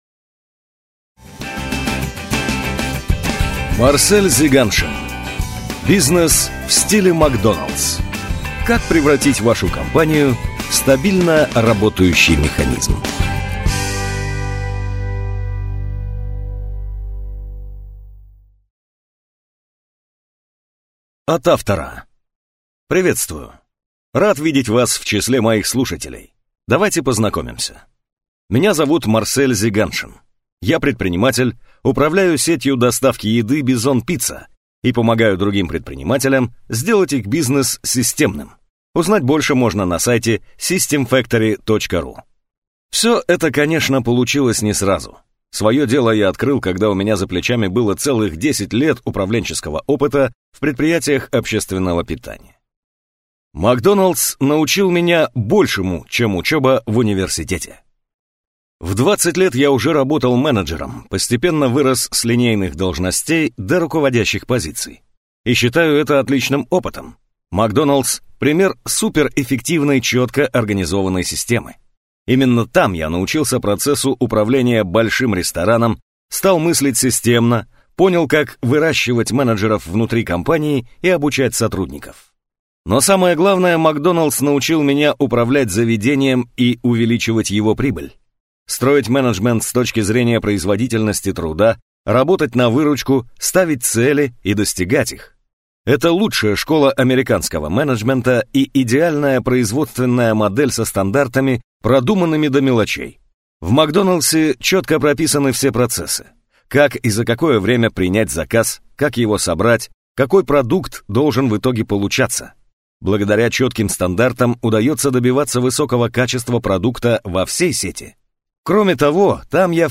Аудиокнига Бизнес в стиле «Макдоналдс». Как превратить вашу компанию в стабильно работающий механизм | Библиотека аудиокниг